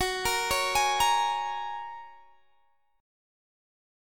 Listen to Gbadd9 strummed